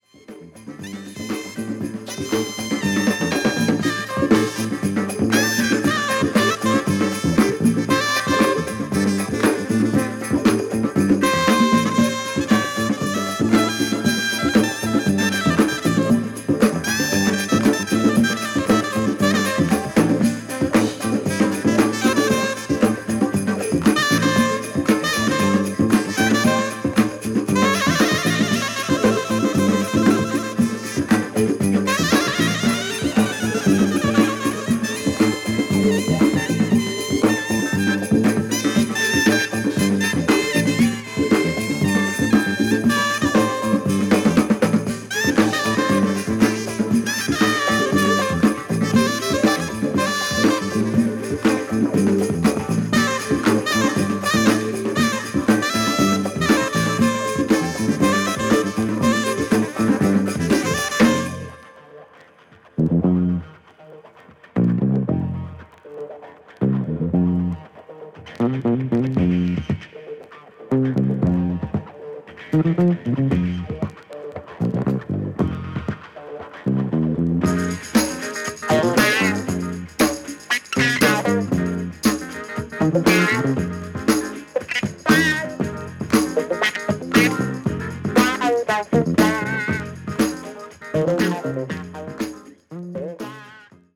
初期作品はDEEP FUNK作品が多いですね。